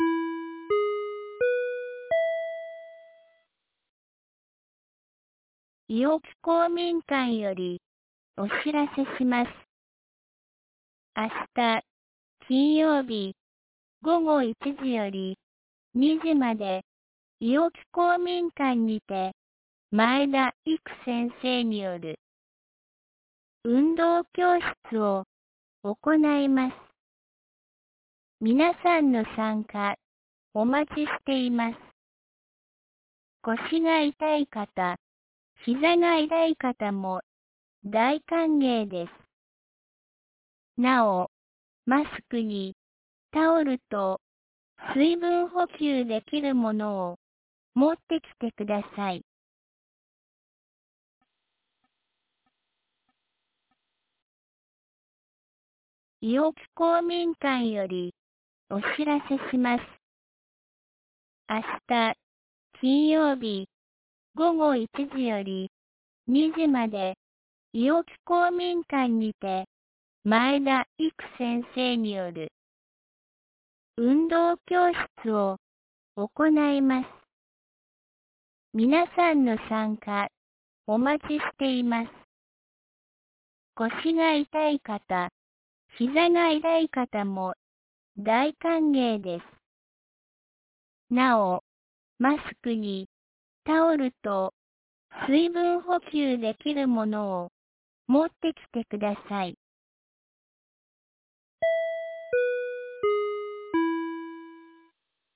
2023年02月02日 17時11分に、安芸市より伊尾木、下山へ放送がありました。